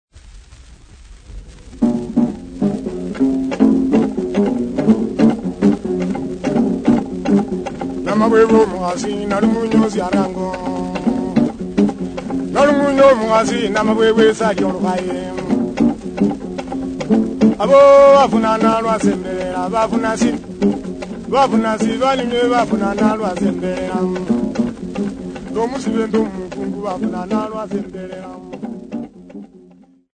Bukusu men
Popular music--Africa
field recordings
Topical song with Litungu lyre and luhingele inverted bowl